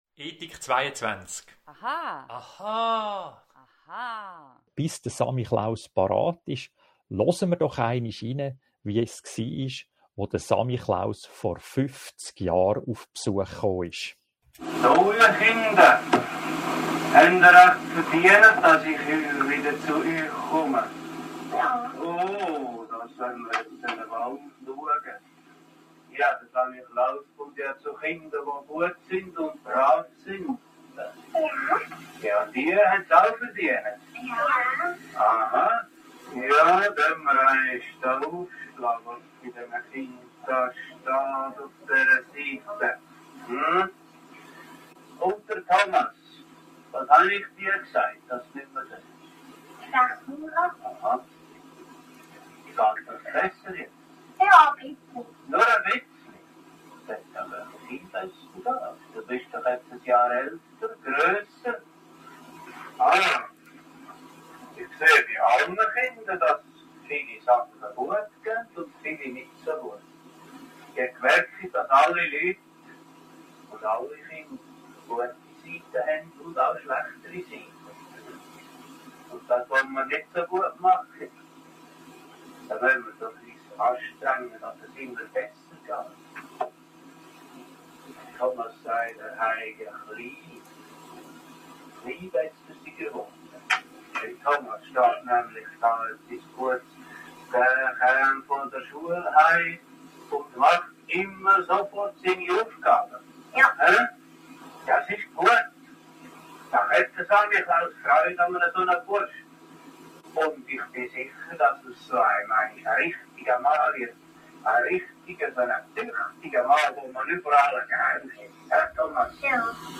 Samichlaus Gast bei Radio🎙einFluss.